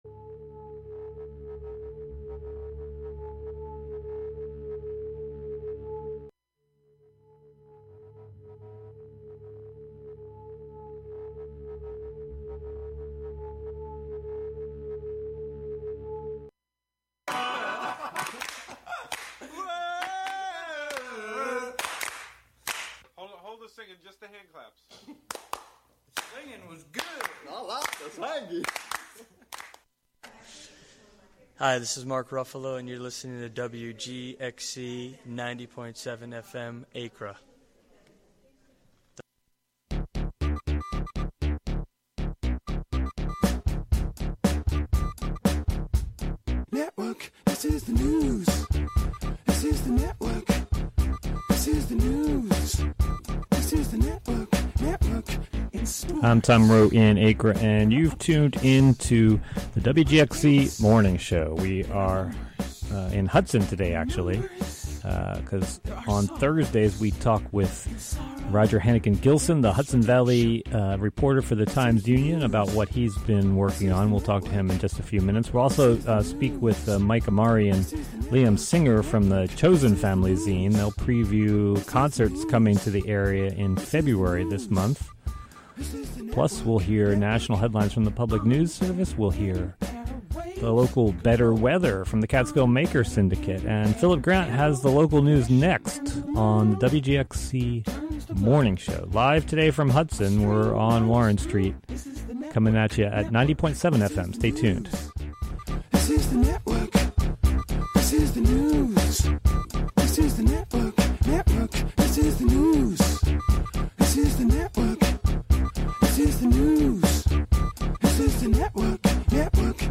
The "WGXC Morning Show" features local news, interviews with community leaders and personalities, a rundown of local and regional events, weather updates, and more about and for the community. The show is a place for a community conversation about issues, with music, and more.